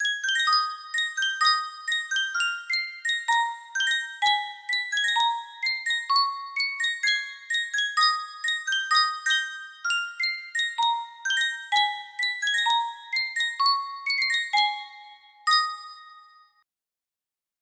Type Full range 60
BPM 128